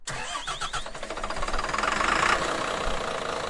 公交车 " 公交车发动机在外面启动
描述：公交车引擎启动
Tag: 外观 启动 总线 交通 发动机